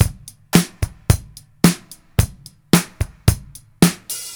• 110 Bpm Drum Loop C# Key.wav
Free drum groove - kick tuned to the C# note. Loudest frequency: 2901Hz
110-bpm-drum-loop-c-sharp-key-eLF.wav